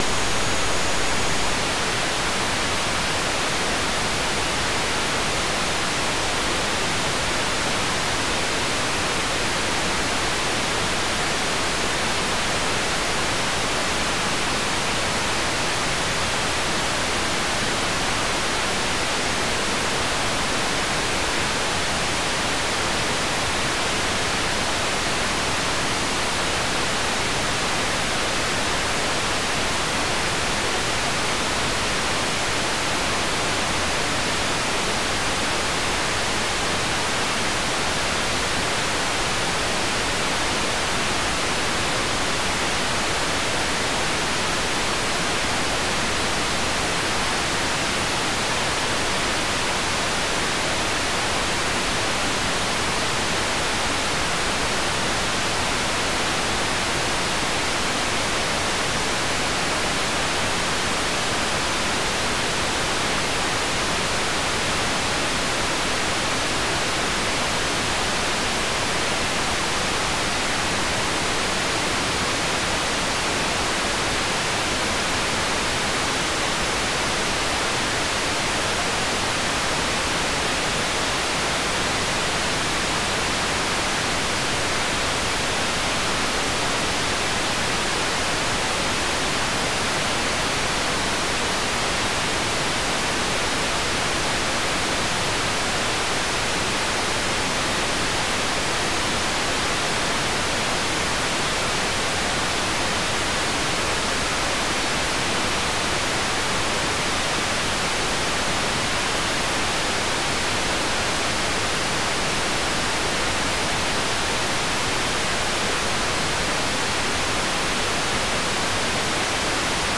"transmitter_description": "Mode V - SSTV - Martin M1 SSTV",